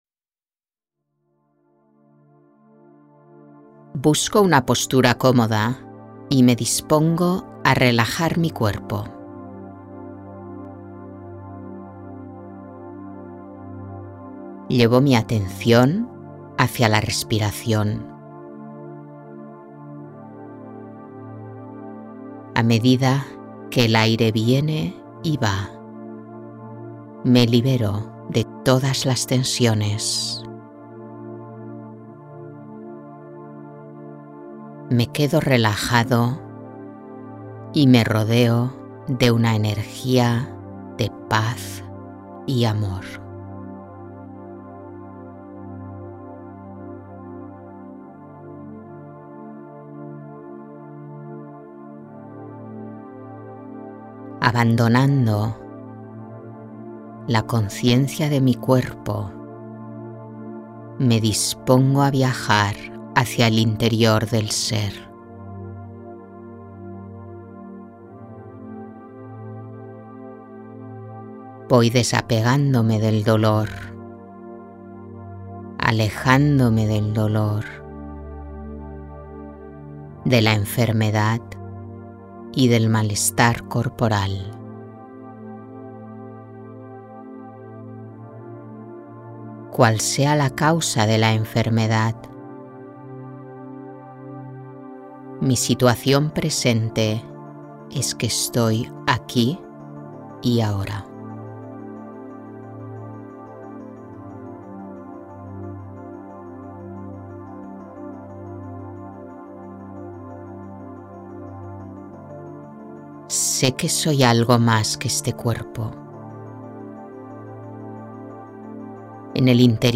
meditaciones-guiadas